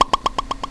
Sound Effects (efx)
stereo sounds developed for mouse interaction on a Macintosh